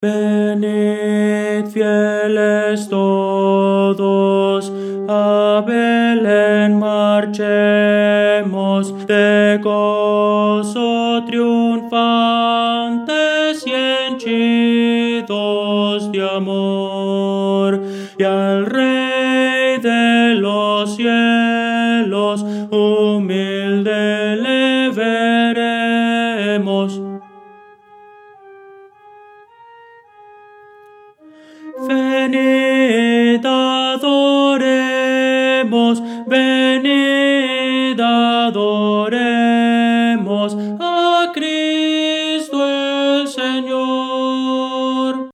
Voces para coro
Tenor – Descarga